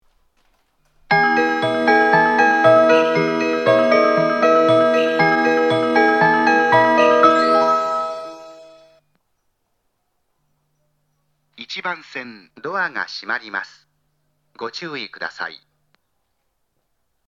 当駅の発車メロディーは音質が大変良いです。
発車メロディー
余韻切りです。